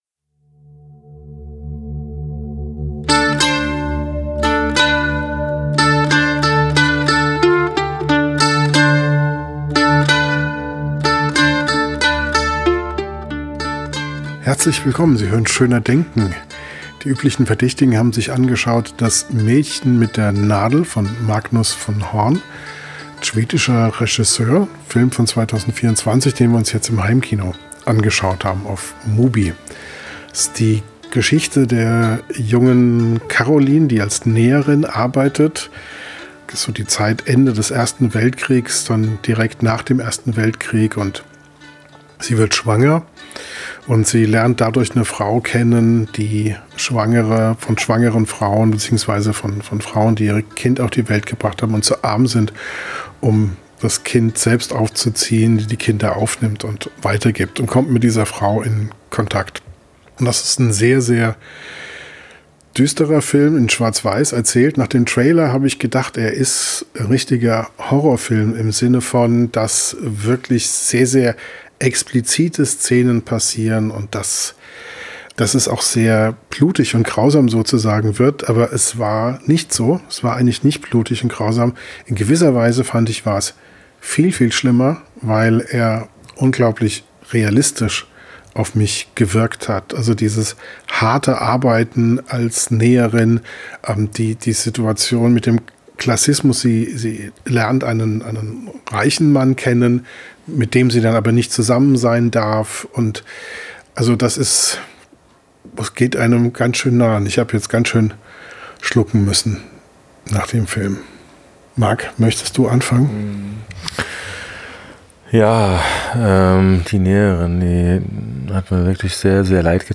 Direkt nach dem Film hört man uns die Betroffenheit an.